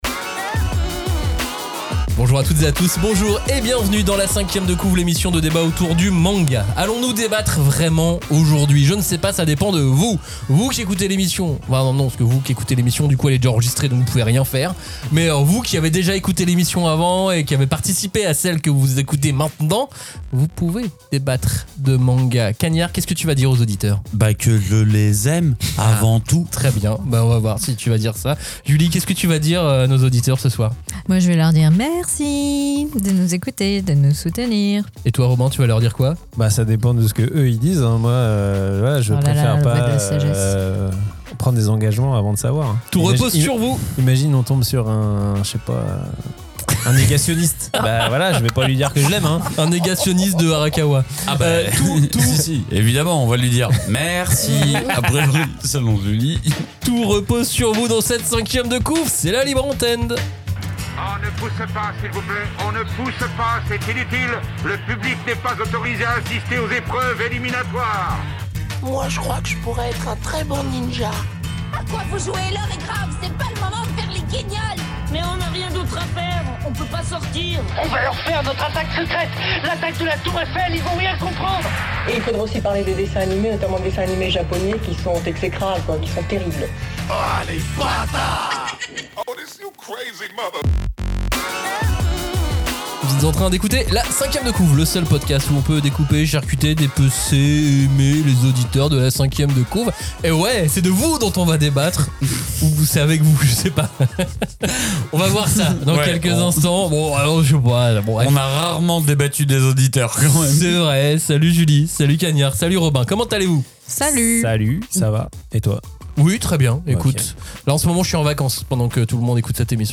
C'est un nouveau rendez-vous annuel : la 5e de Couv' donne la parole à ses auditeurs en mode libre antenne !